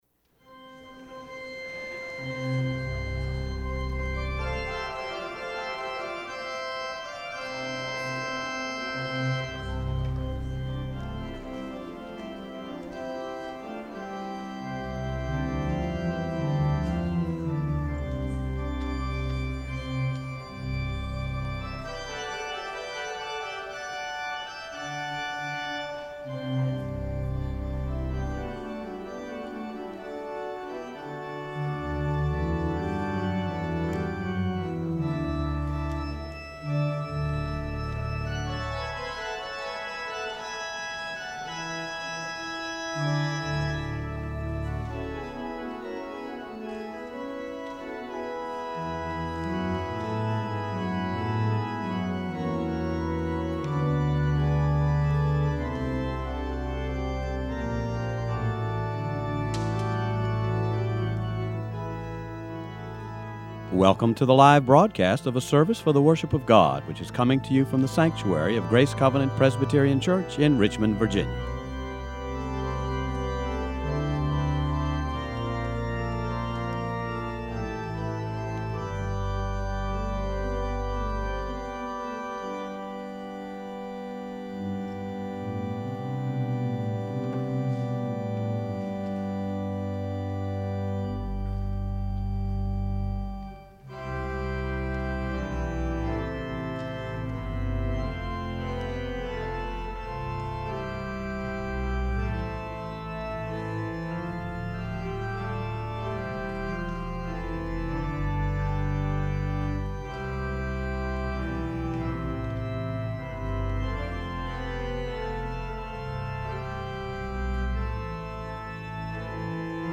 guest organist